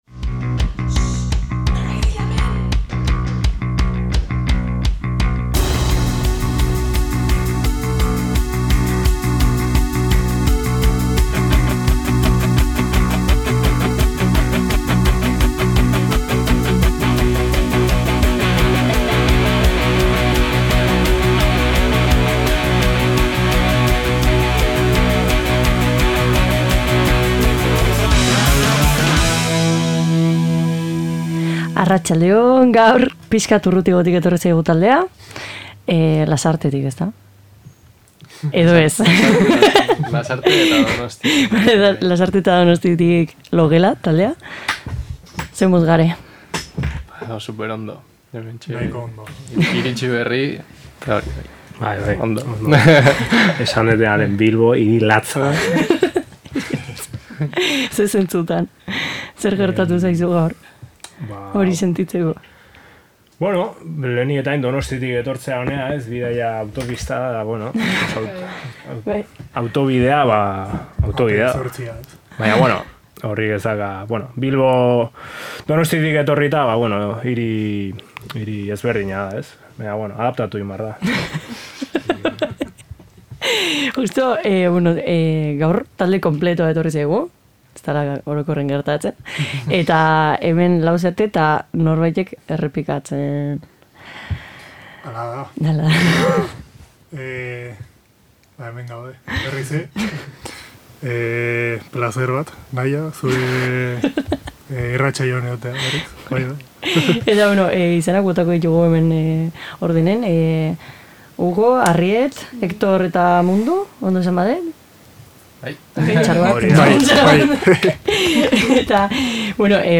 Gurekin, irratian: Logela. Laukote zintzo eta jator hau aurreko urtean aurkeztu zaigu, eta orain, haien proiektuaren bueltako ideia eta asmo batzuk azaldu dizkigute.
Baina orain, gogoz espero dugun diskoa atera arte, saio honen bidez pare bat kanta entzuteko aukera izango dugu.